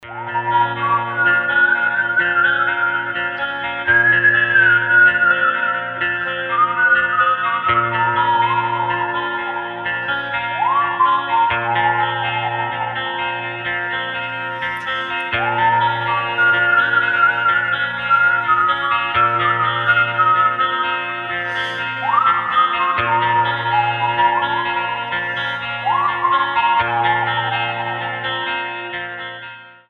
• Качество: 320, Stereo
гитара
свист
мелодичные
Electronic
спокойные
без слов